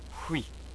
UI - "hwee" — The H is an unvoiced glottal fricative, like
UI itself is simply a 'w' sound followed
with the sound "ee" as in keep.